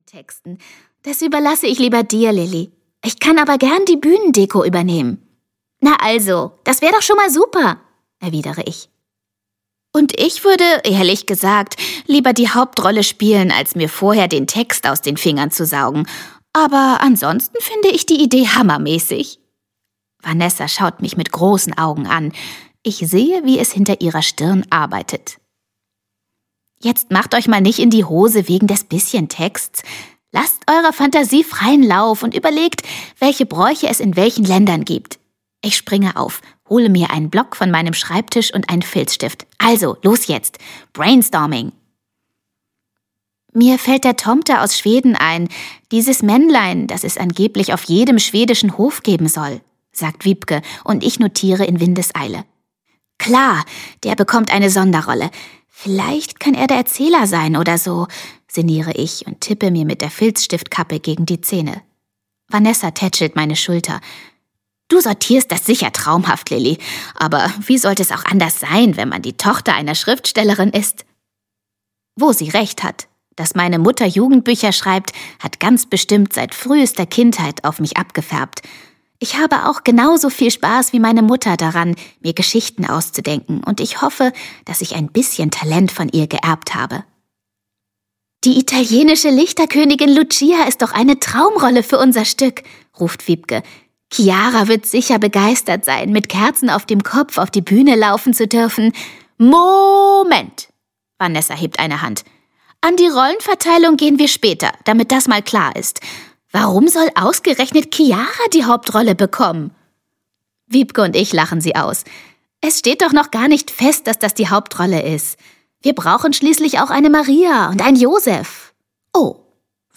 Freche Mädchen: Weihnachtsküsse aus aller Welt - Martina Sahler - Hörbuch